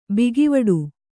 ♪ bigivaḍu